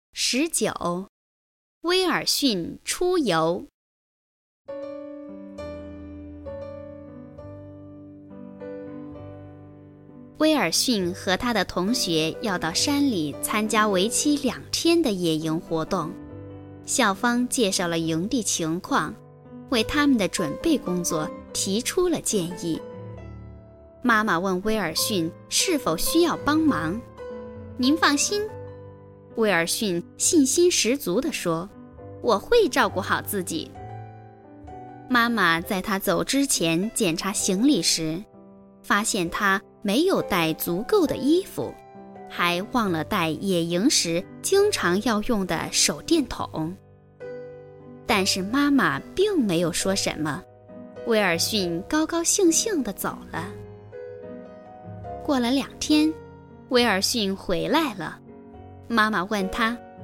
语文三年级上西师版19《威尔逊出游》课文朗读_21世纪教育网-二一教育